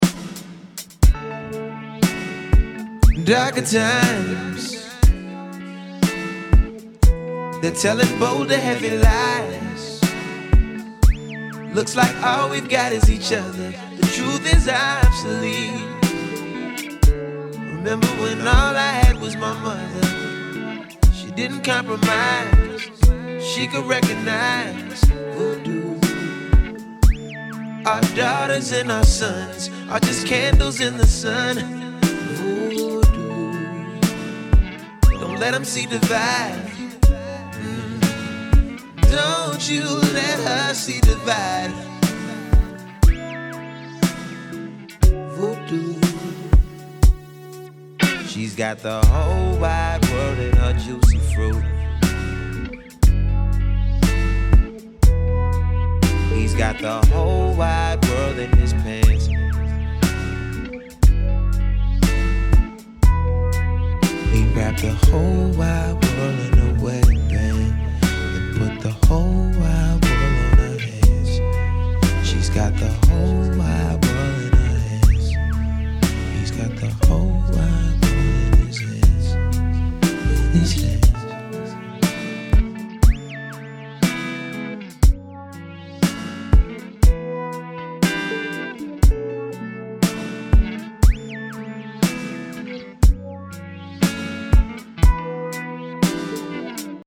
short ballad